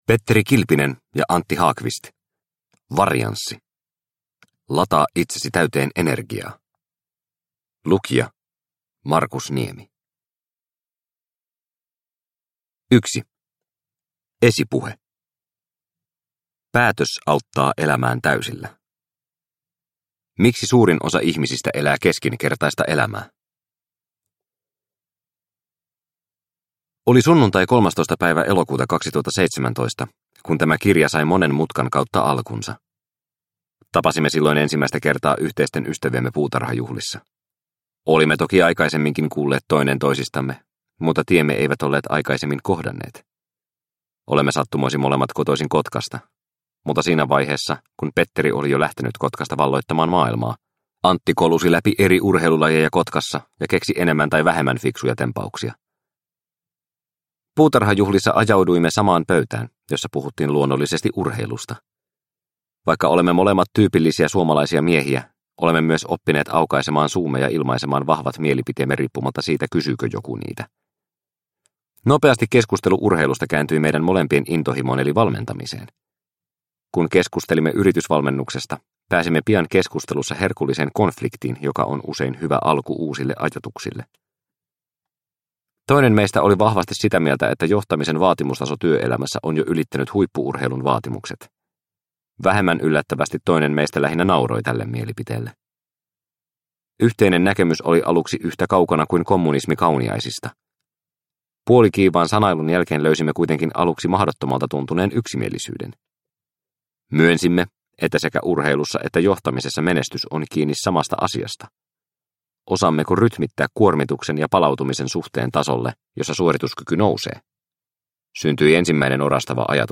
Varianssi – Ljudbok – Laddas ner